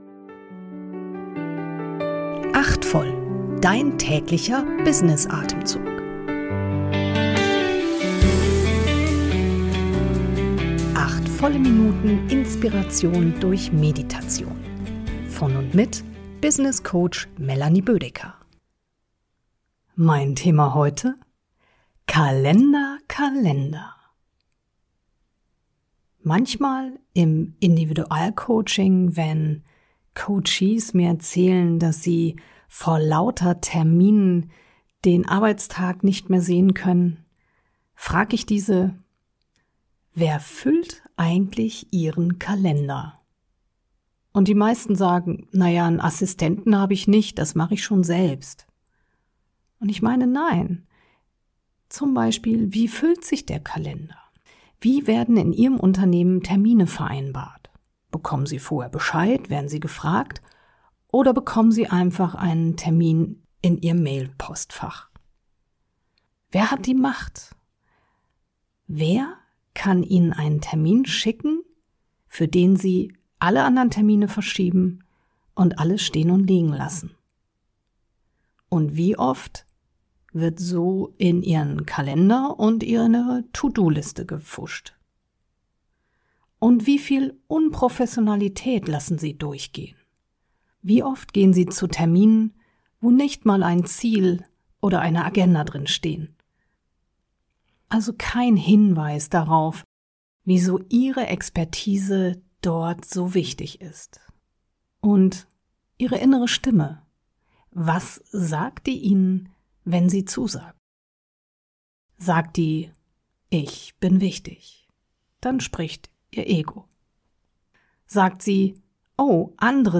Erfrischung durch eine geleitete Kurz-Meditation.